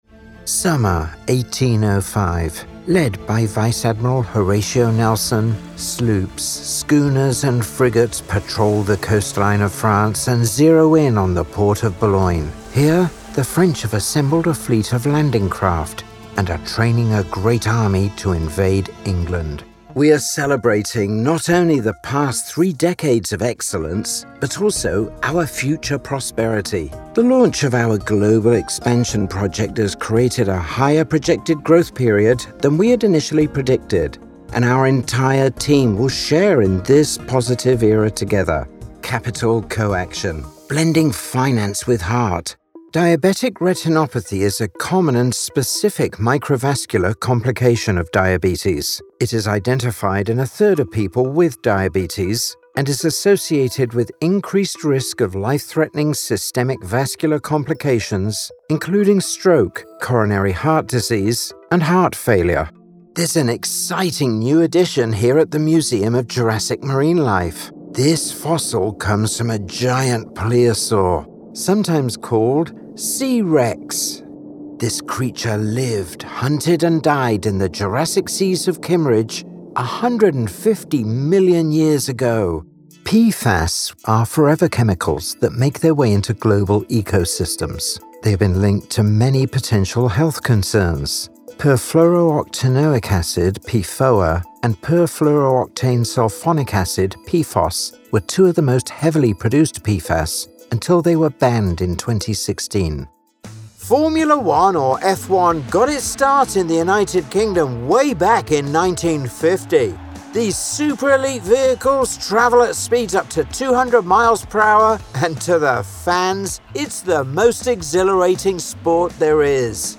I can be authoritative, friendly & conversational, nurturing and a bit cheeky - occasionally all at the same time!
English - British RP
RP but also a variety of regional British dialects
Middle Aged